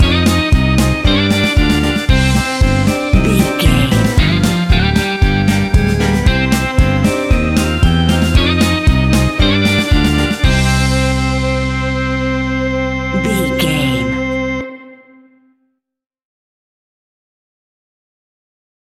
Aeolian/Minor
scary
ominous
eerie
groovy
drums
electric guitar
bass guitar
piano
synthesiser
Scary Synths